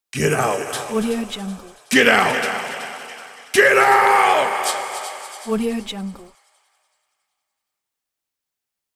Scary Voice Screaming Get Out Sound Button: Unblocked Meme Soundboard